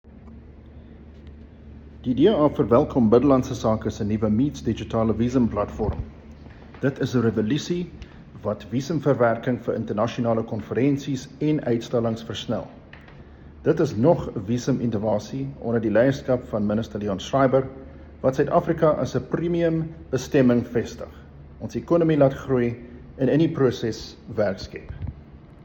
Issued by Adrian Roos MP – DA Spokesperson on Home Affairs
Afrikaans soundbite by Adrian Roos MP.